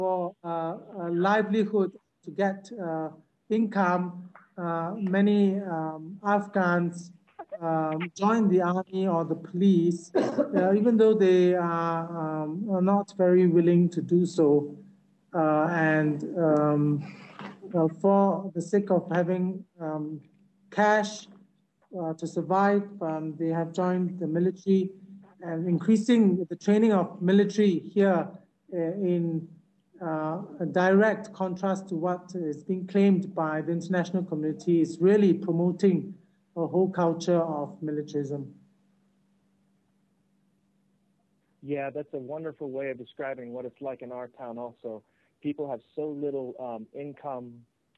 I was able to record portions of the conversation, as it was streamed over the Internet.
Hear these comments here:One of the Afghans told us that many Afghans reluctantly join the military or the police, because they need the income.  He said that this has promoted a “culture of militarism.”